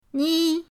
ni1.mp3